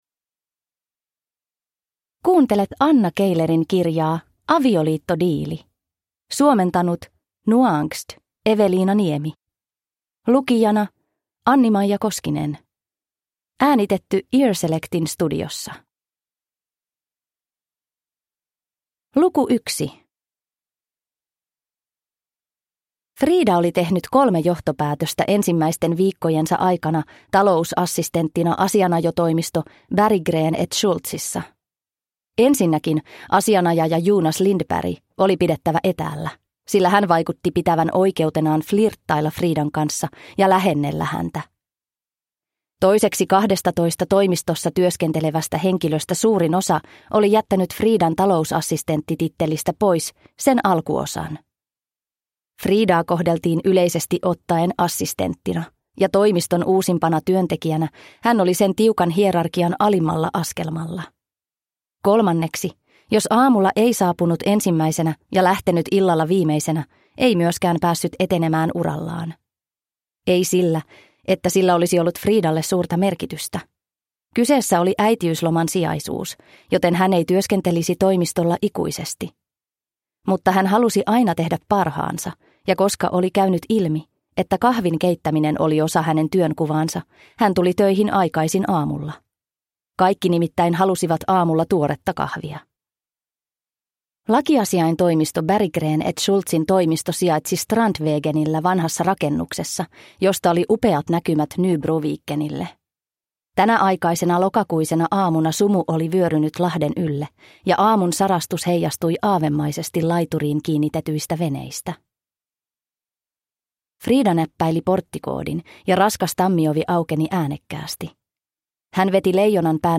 Avioliittodiili – Ljudbok